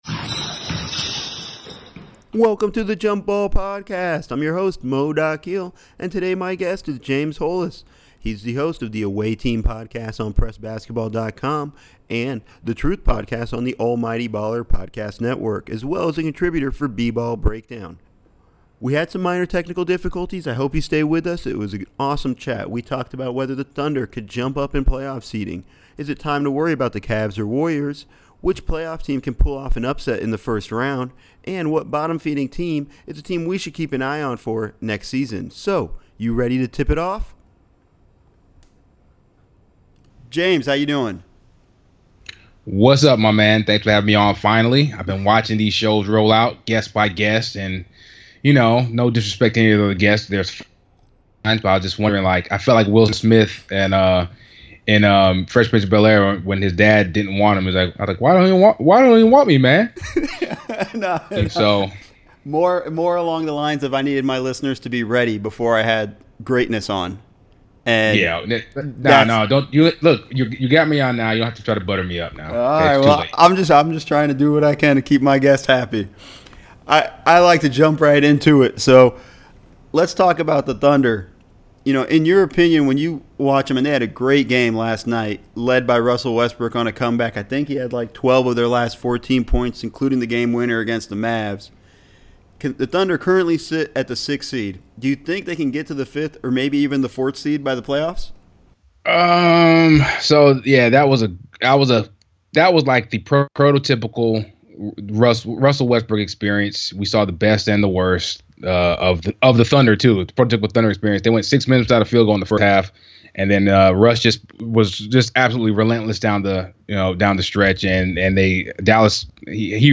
Had some minor technical difficulties but still an awesome conversation.